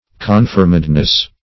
Confirmedness \Con*firm"ed*ness\, n.
confirmedness.mp3